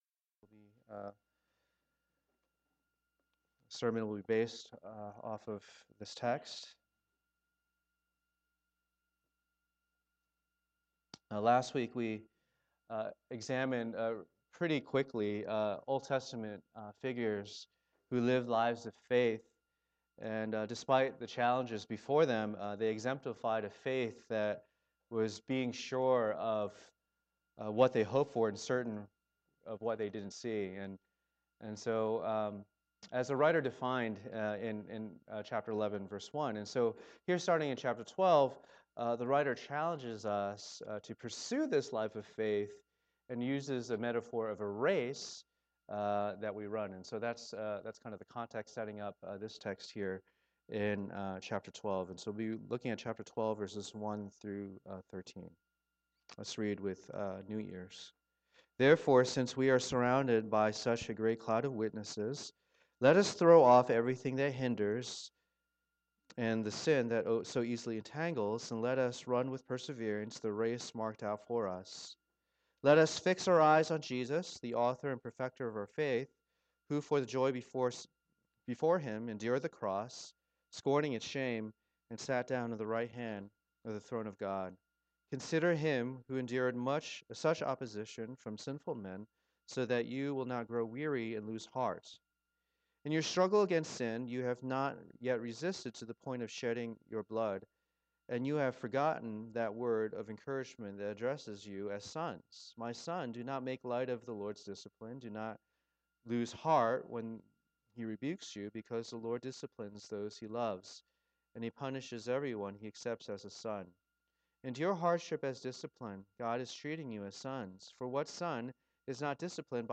Passage: Hebrews 12:1-13 Service Type: Lord's Day